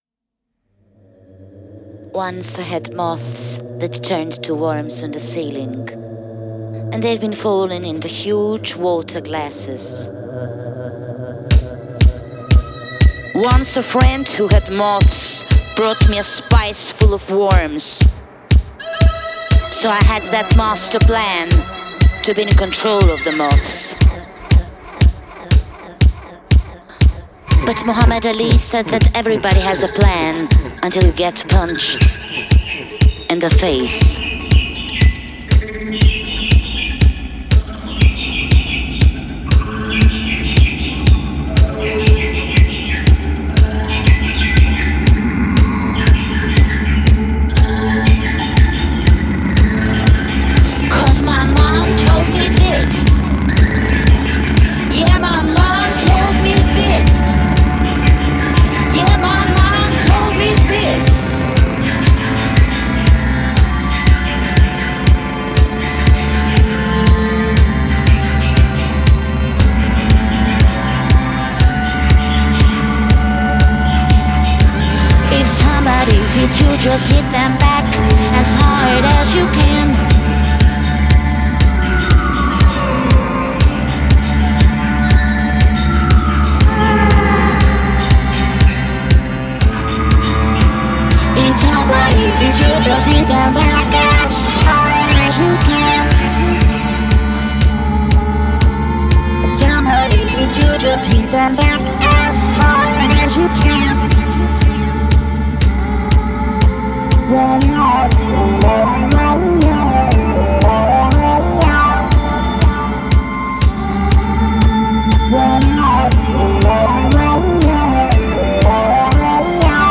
The Washing Machine
Vacuum Cleaner Dirt Devil
Sewing Machine Deutsche Wertarbeit